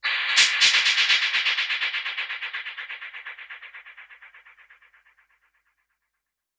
Index of /musicradar/dub-percussion-samples/125bpm
DPFX_PercHit_B_125-08.wav